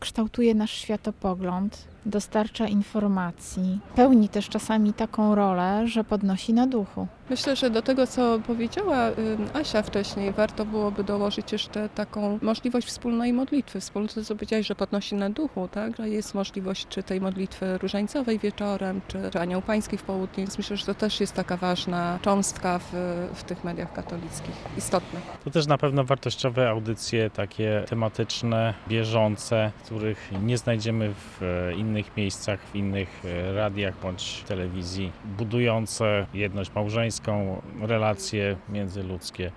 Słuchacze z kolejnych parafii podkreślali, że Radio Warszawa towarzyszy im w codzienności.